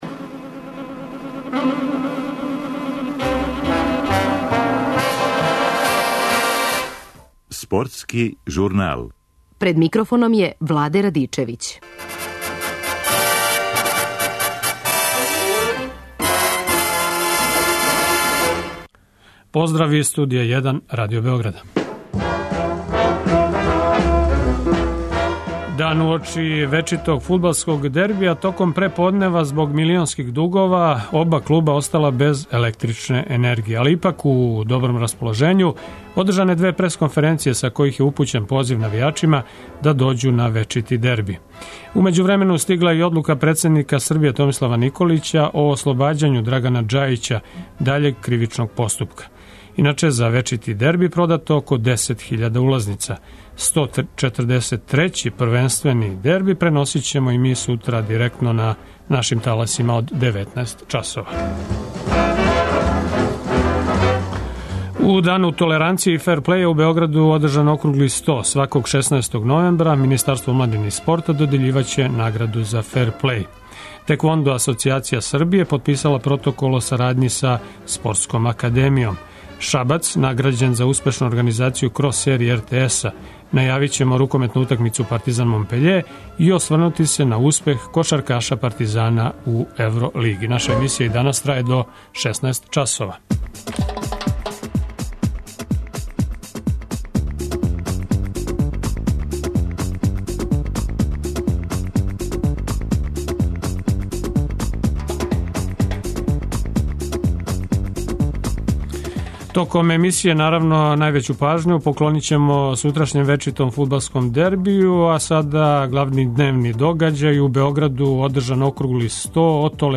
Пред вечити дебри за Београд 1 говоре играчи и тренери оба тима.